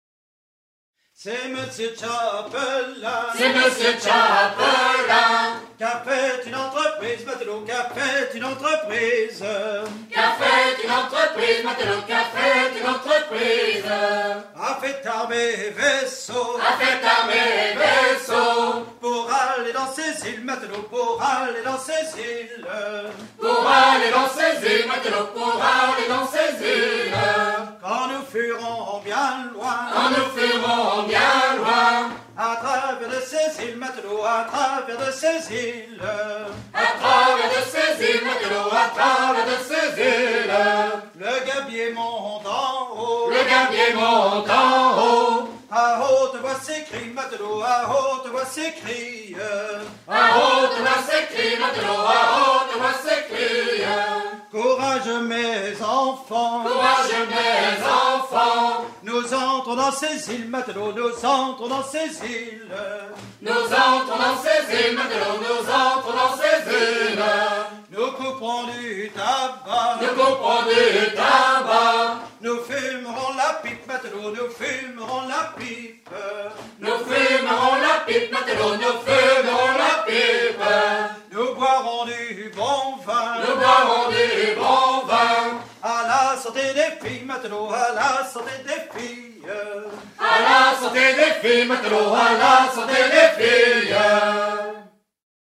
Version recueillie en 1978
Pièce musicale éditée